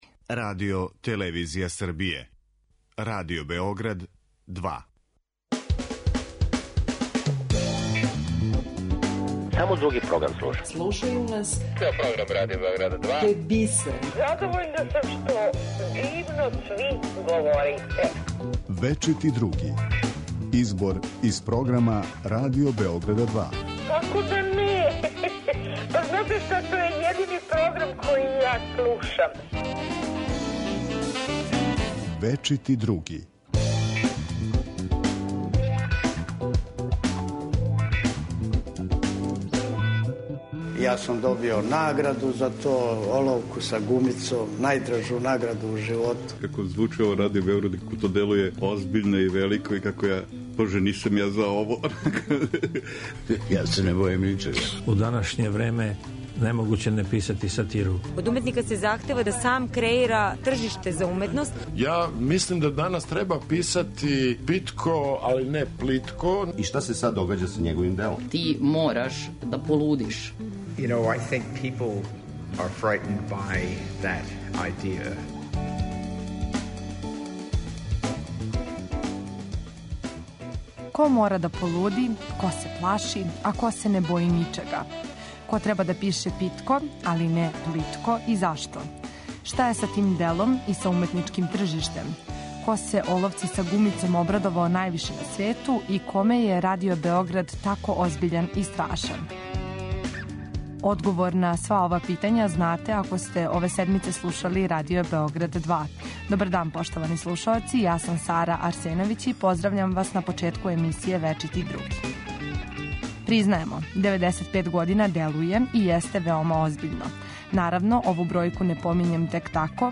Избор из програма Радио Београда 2